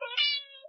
nc_cats_mew.7.ogg